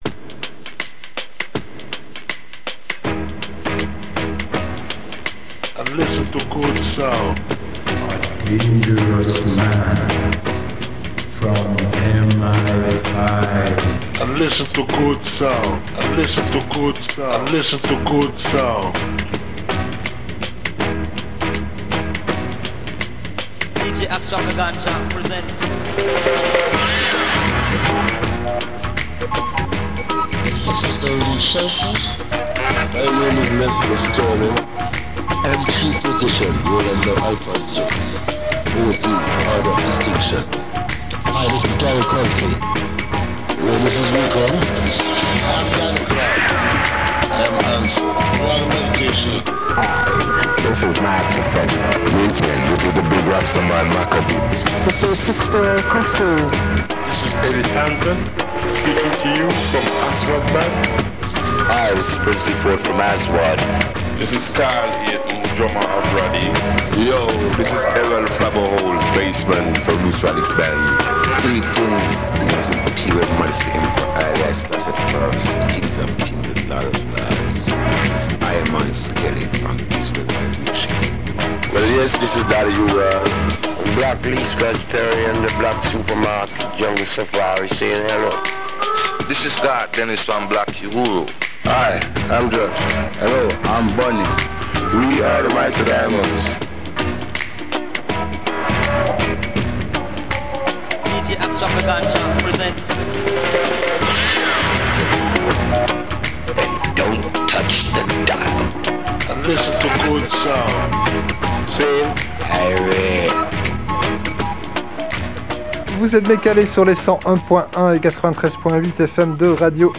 Black Super Market – radio show !
ska, afrobeat, dub, salsa, funk, mestizo, kompa, rumba, reggae, soul, cumbia, ragga, soca, merengue, Brésil, champeta, Balkans, latino rock…